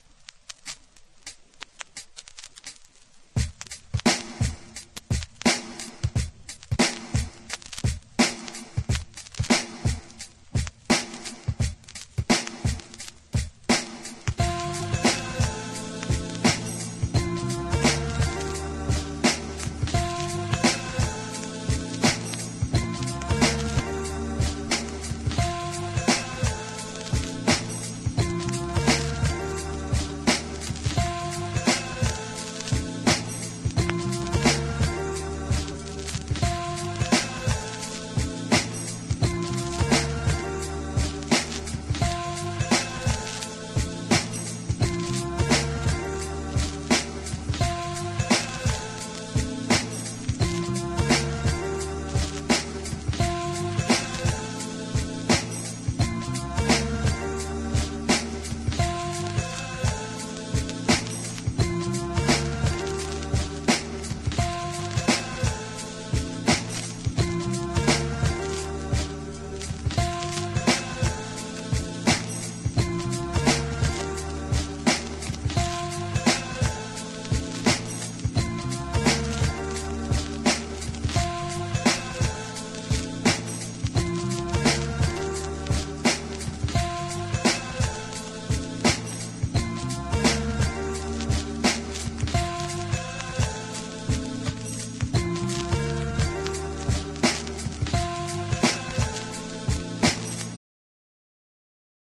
傑作2NDアルバムのインスト集!!